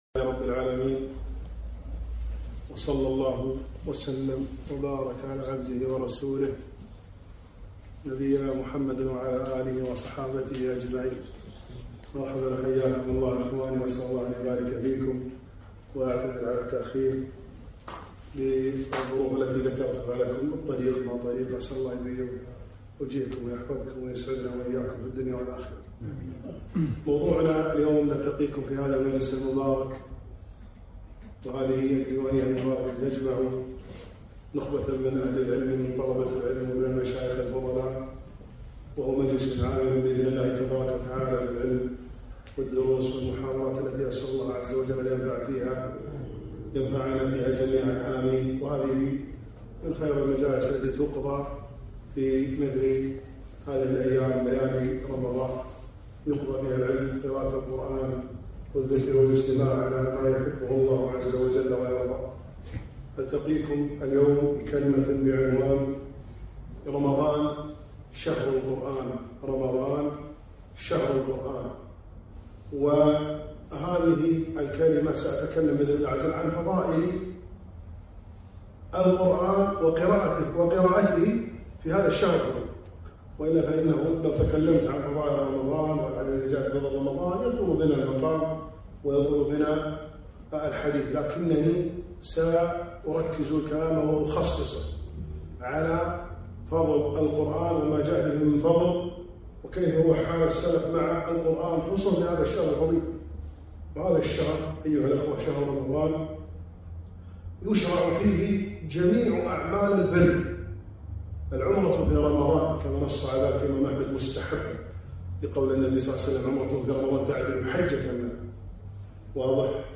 محاضرة - رمضان شهر القرآن